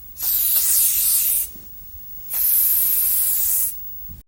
Звук аэрозольного баллончика